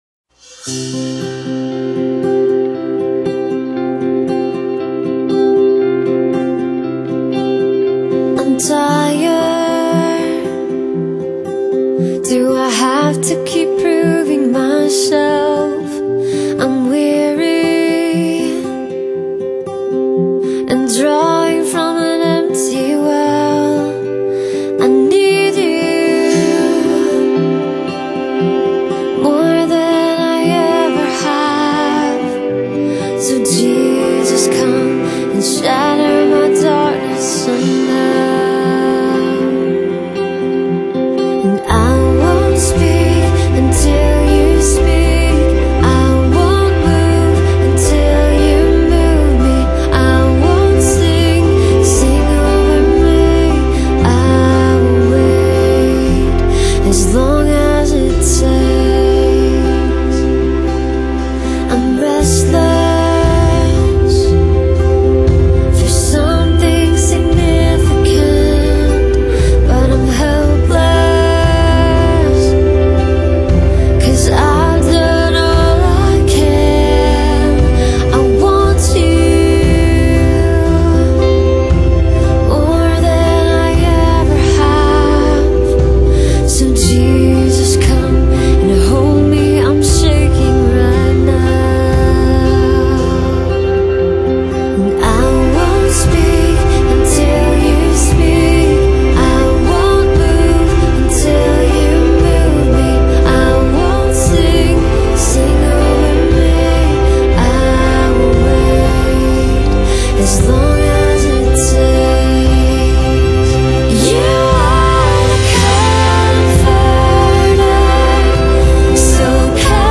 Christan/Pop
轻盈而甜美的声线，柔柔的，听着很舒服